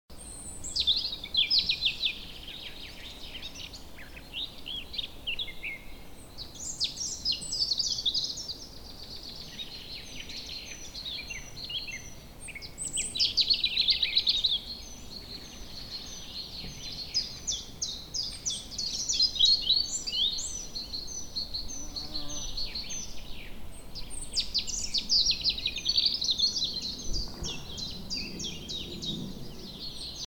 nature.DYaaIlX_.mp3